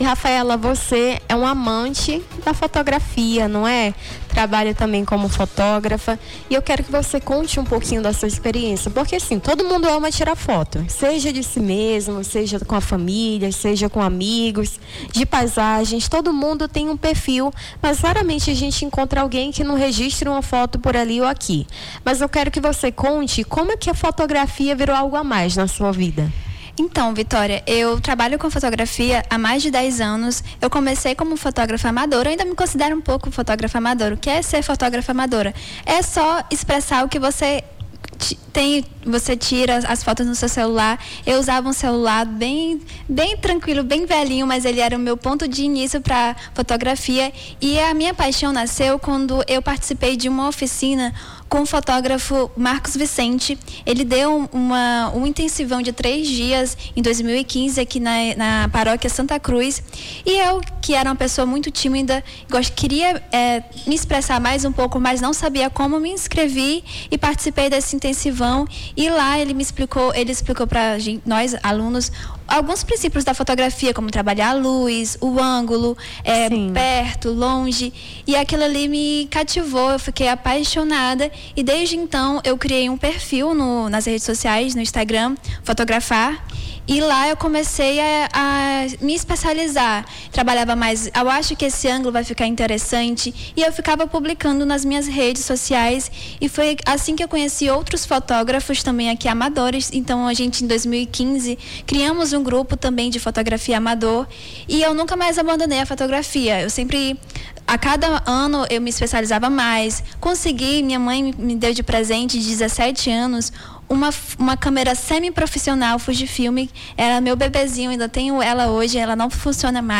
Nome do Artista - CENSURA - ENTREVISTA (DIA DO FOTOGRAFO) 19-08-25.mp3